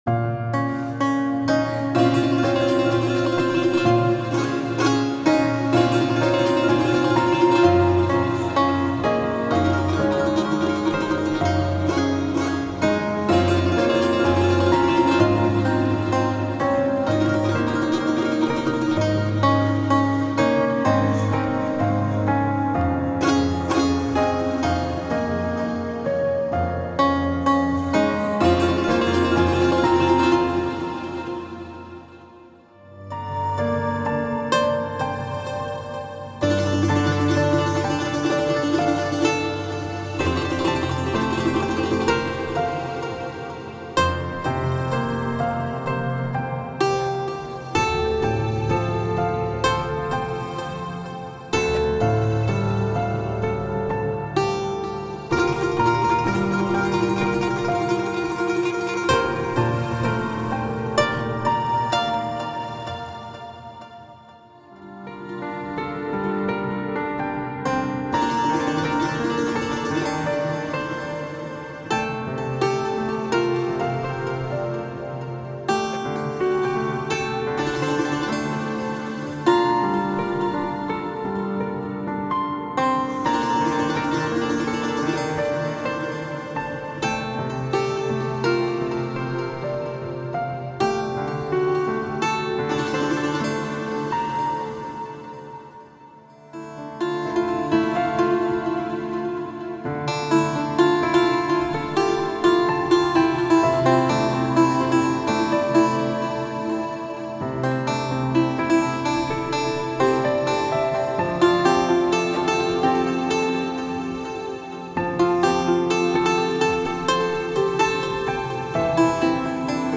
Type: Midi Samples
KOPUZ + PIANO ( Dry & Wet per kit )